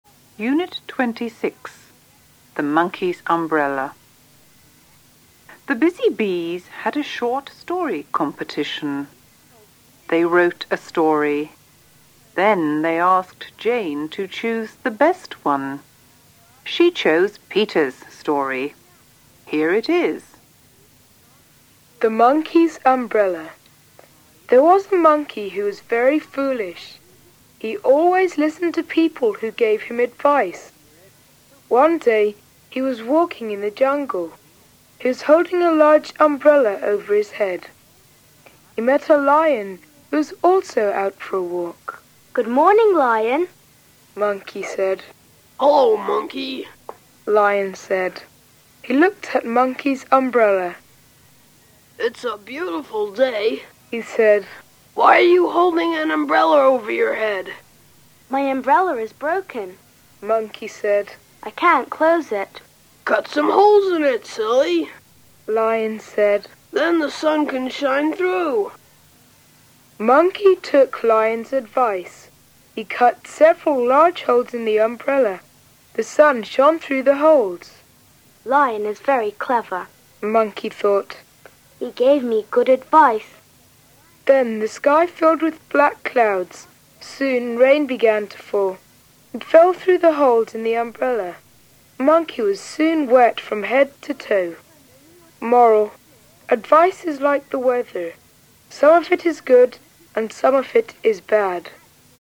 小学生听力的提高是需要多练习的，这一套听力材料朗读清晰，很适合五年级学生练习听力。